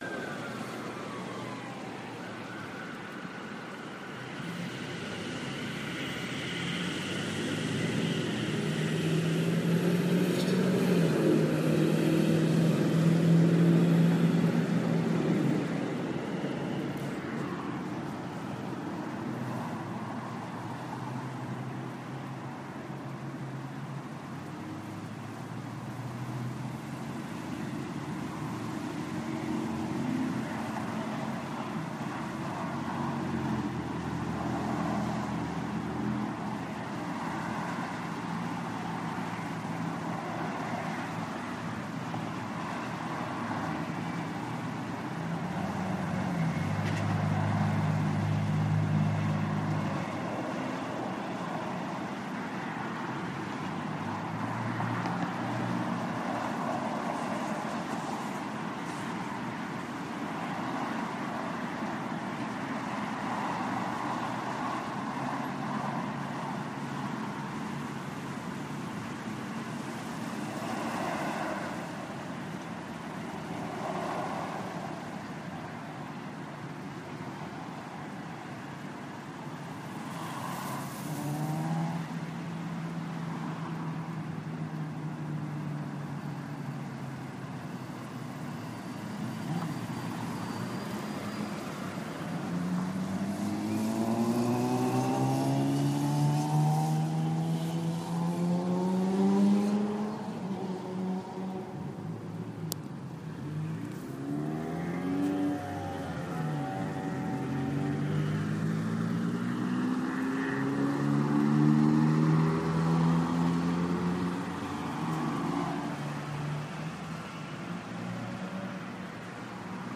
火车
描述：用Zoom H4n，Beyerdynamic MC 837，Sony ECMNV1录制
标签： 火车 地铁 音景 环境 郊区 郊区的人 一般的噪声 城市
声道立体声